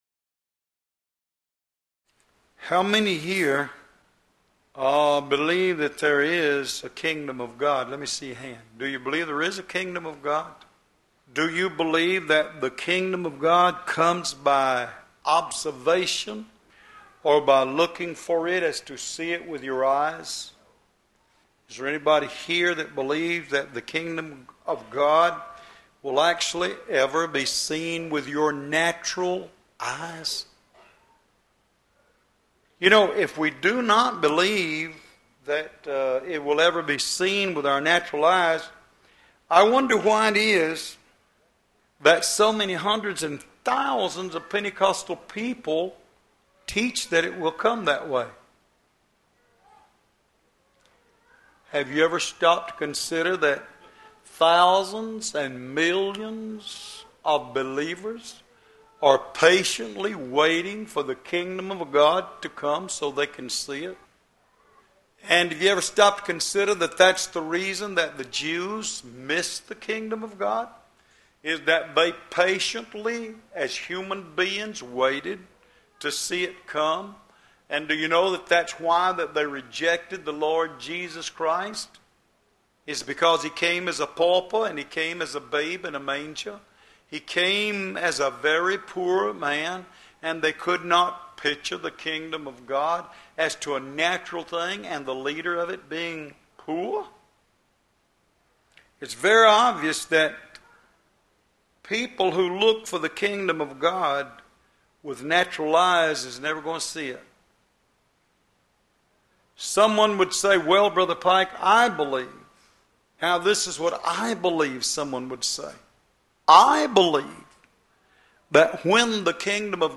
Location: Love’s Temple in Monroe, GA USA
Sermons